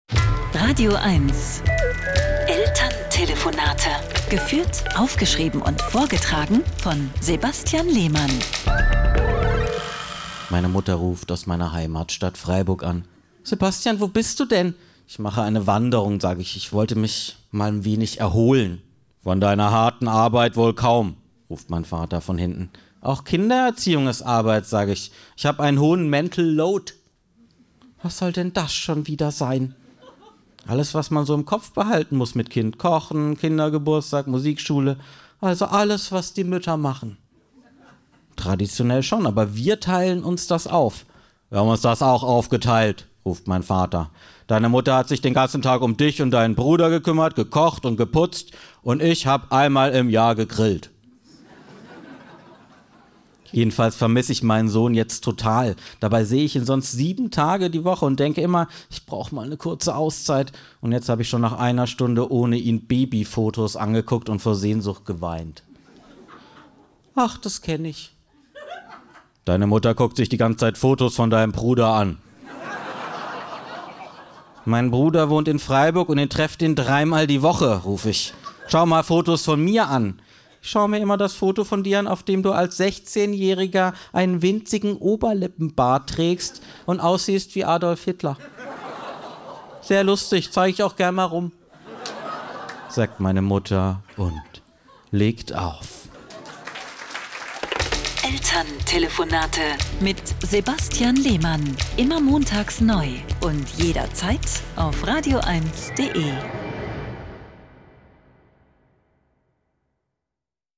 … continue reading 79 Episoden # Saubere Komödien # Komiker Chat # radioeins (Rundfunk Berlin-Brandenburg # Komödie # Unterhaltung